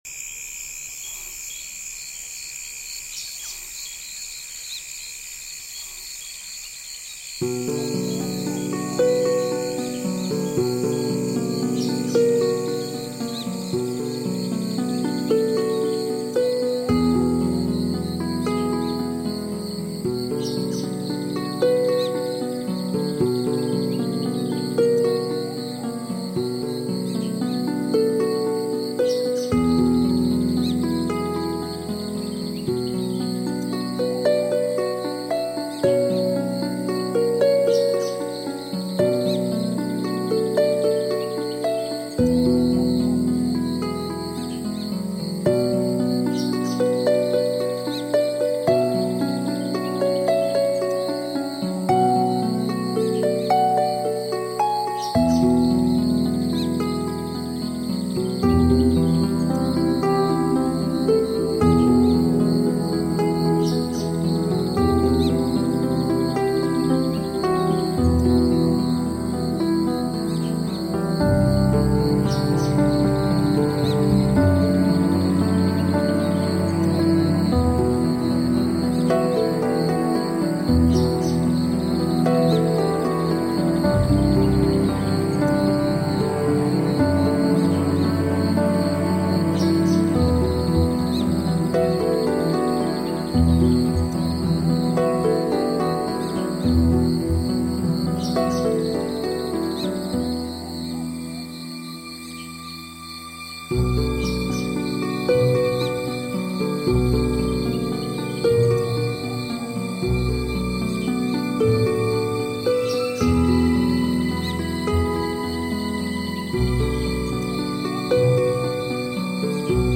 秘錄藏傳梵音！
運用鯨之歌、暴雨、蟋蟀、獅吼等大自然原始聲響， 與當代樂器，加入鼓、笛子、西塔琴、小提琴、中東烏德琴
低音梵唱有如穿越時空
忽遠忽近的鼓聲自四面八方重擊而來，層層音場 包圍